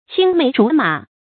成語注音ㄑㄧㄥ ㄇㄟˊ ㄓㄨˊ ㄇㄚˇ
成語拼音qīng méi zhú mǎ
青梅竹馬發音
成語正音竹，不能讀作“zú”。